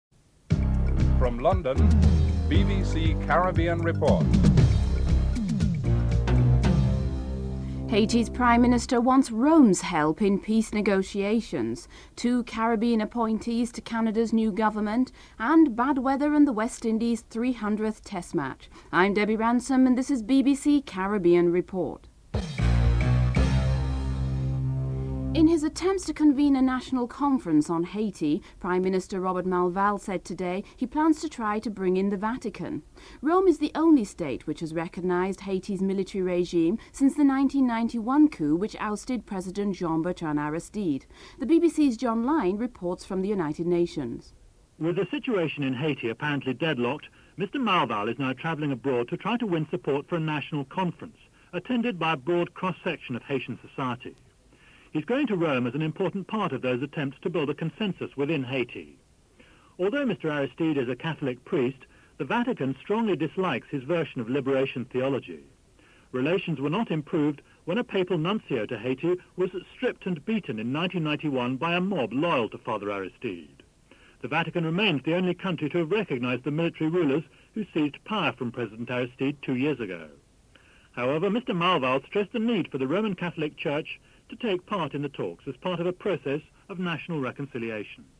Headlines with anchor
2. Norwegian Caribbean – a major US based cruise line will drop Jamaica from its ports of call due to an increase in Jamaica’s passenger tax – Carlyle Dunkley, Minister of Tourism Jamaica gives his feedback on this issue (04:19 - 06:37).